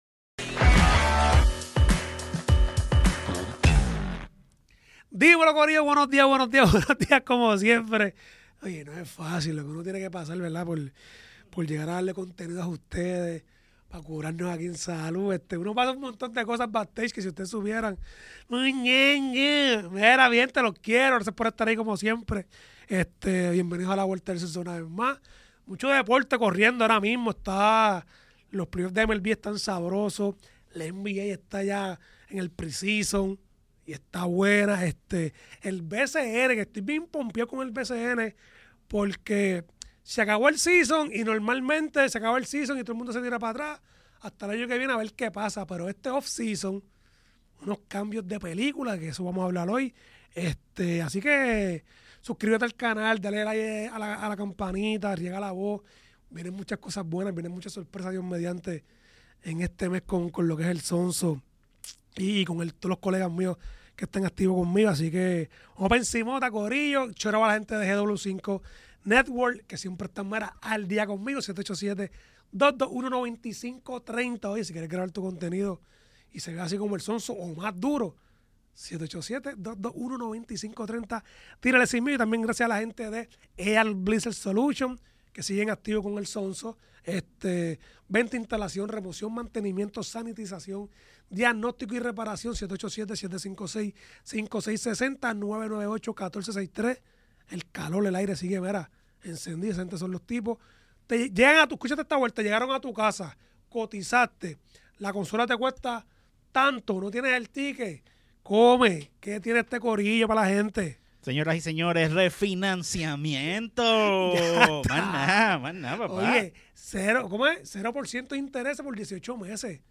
Grabado en GW5 Studios.